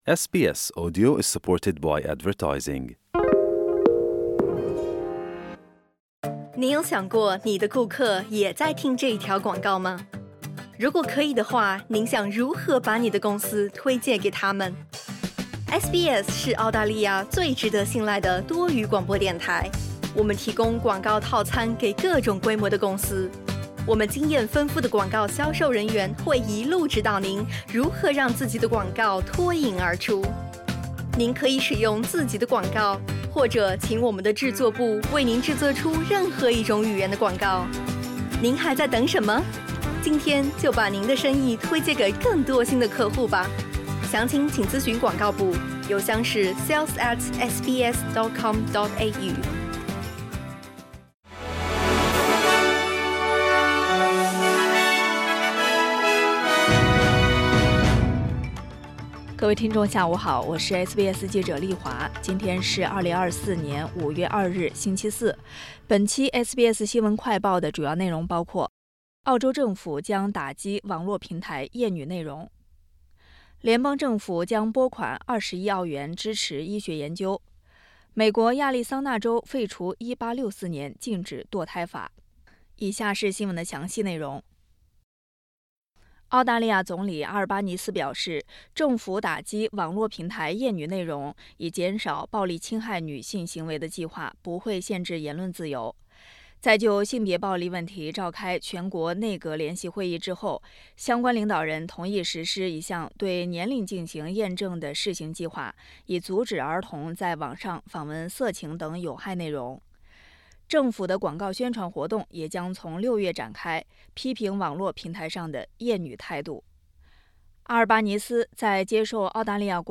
【SBS新闻快报】澳洲政府将打击网络平台厌女内容